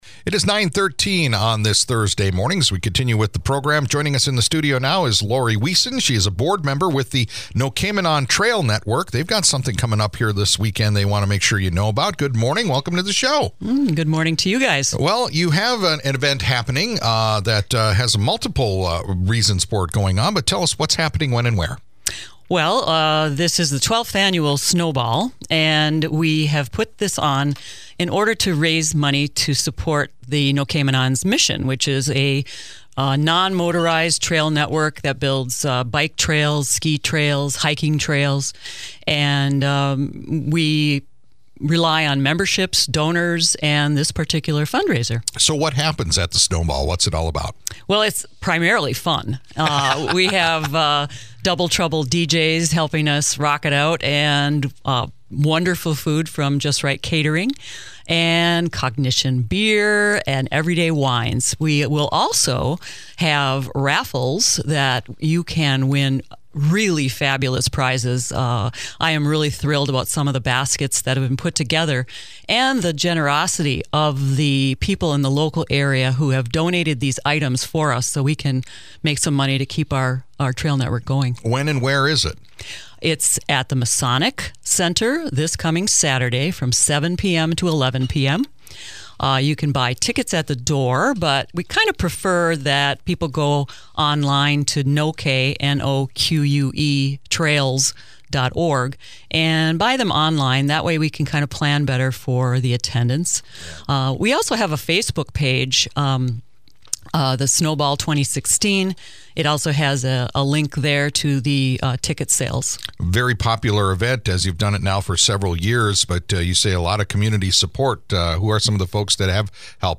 Marquette, MI – (Great Lakes Radio News) – The Noquemanon Trail Network is hosting their annual Snowball this weekend at the Masonic Building in downtown Marquette.